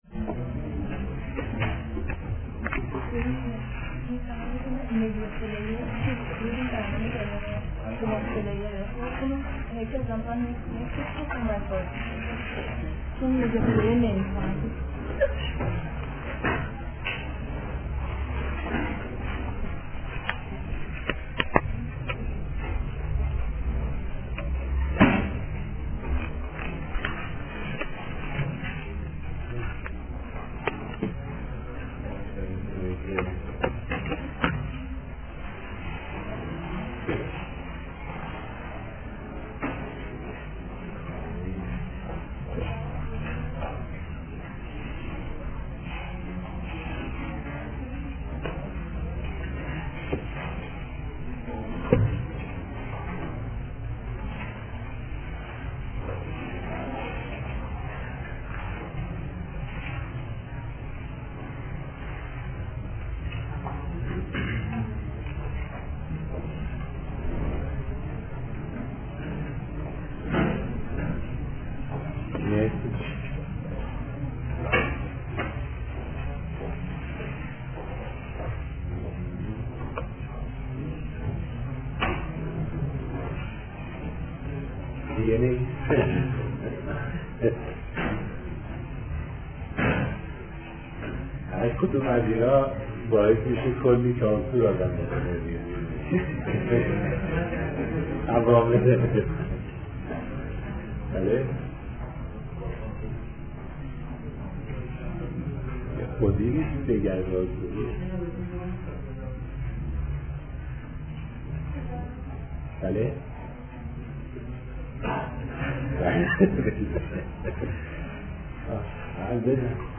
فرهنگ امروز: فایل حاضر قسمت ششم درس‌گفتارهای ماکیاوللی است که «سیدجواد طباطبایی» سال‌ها پیش آن را تدریس کرده است.